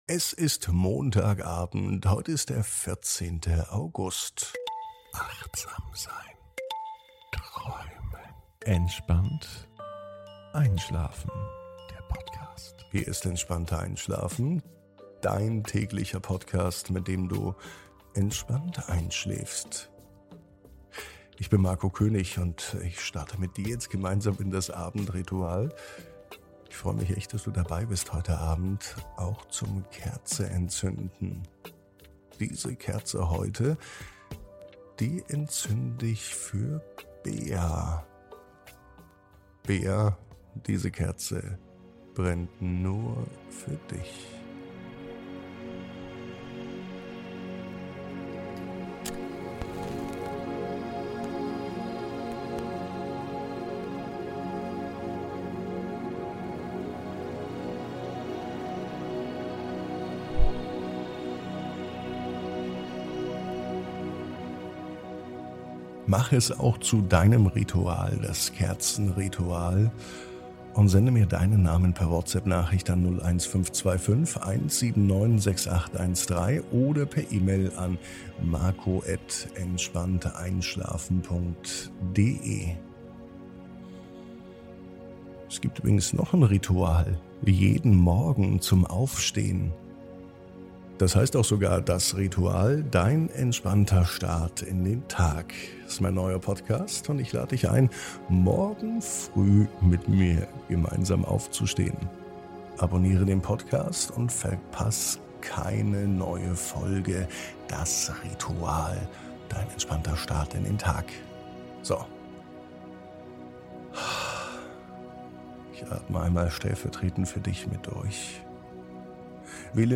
Tauche immer tiefer in die geheimnisvolle Unterwasserwelt hinab und entdecke die Schönheit der farbenprächtigen Korallenriffe und ihrer faszinierenden Bewohner. Die sanften Klänge der Meereswelt begleiten dich auf deiner Reise zu innerer Ruhe und Gelassenheit.
Spüre die heilende Kraft des Ozeans und lasse dich von den harmonischen Klängen der Natur in eine erholsame Nacht begleiten.